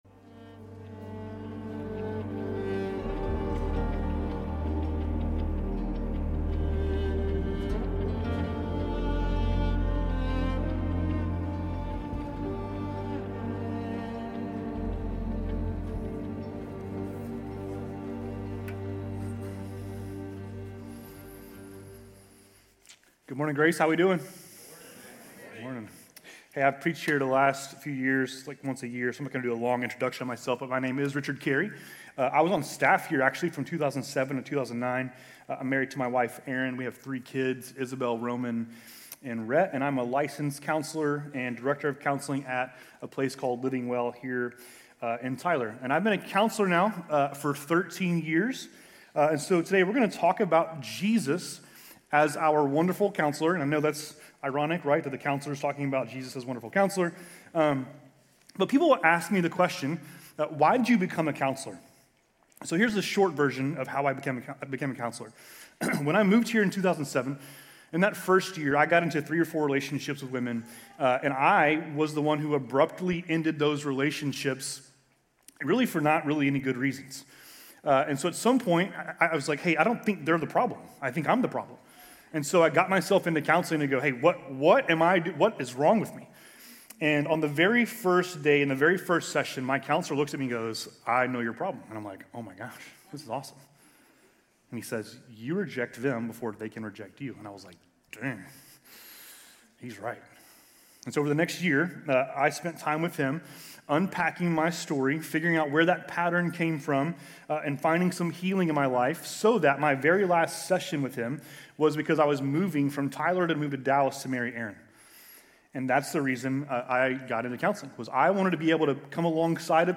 Grace Community Church University Blvd Campus Sermons 12_14 University Blvd Campus Dec 15 2025 | 00:28:39 1x 00:00 / 00:28:39 Subscribe Share RSS Feed Share Link Embed